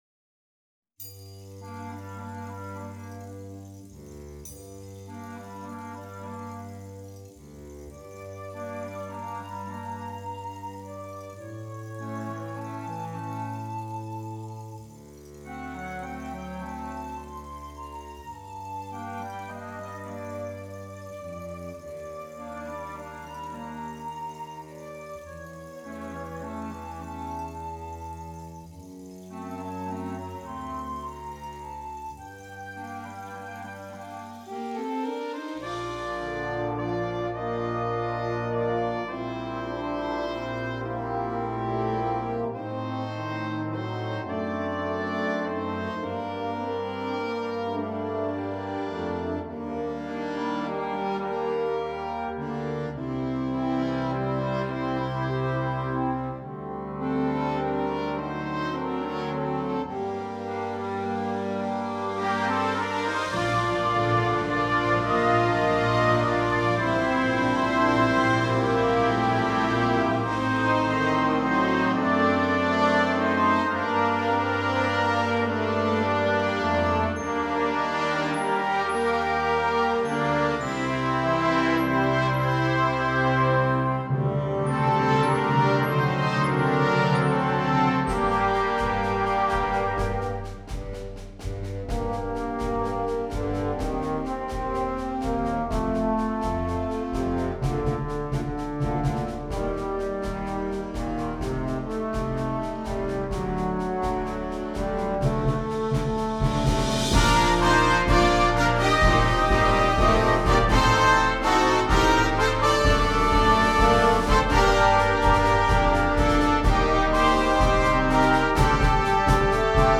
Gattung: Konzertwerk